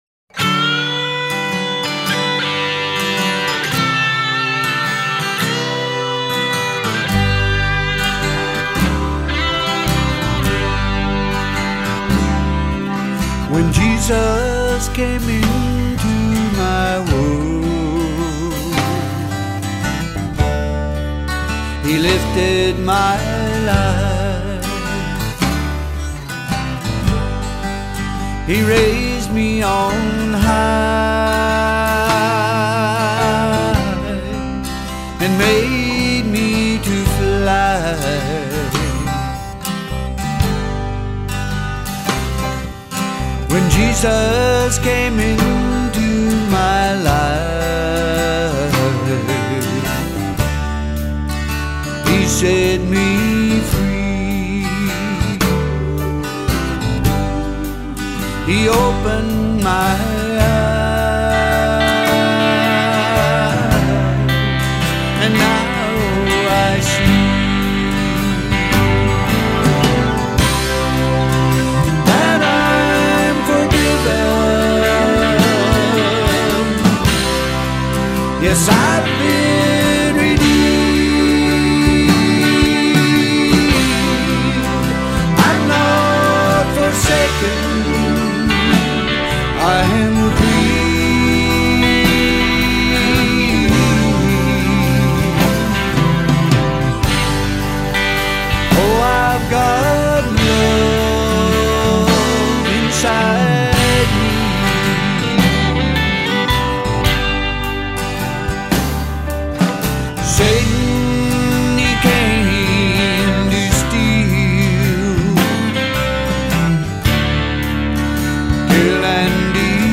gospel singing brothers
keyboards